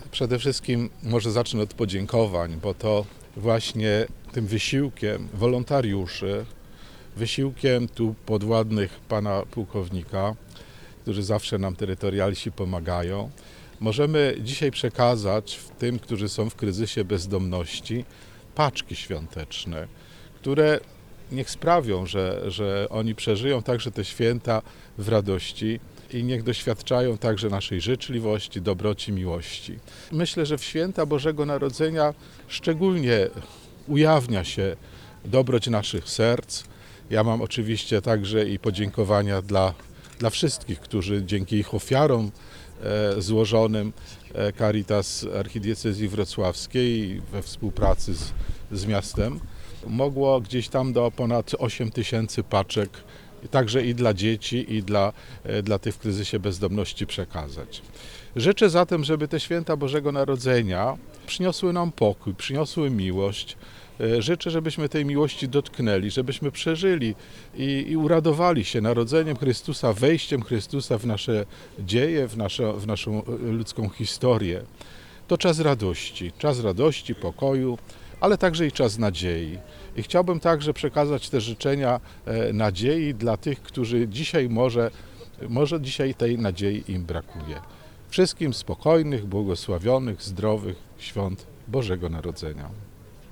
Poświęcenia paczek i opłatków, które trafiły do najuboższych dokonał abp metropolita wrocławski Józef Kupny, który złożył również wszystkim życzenia.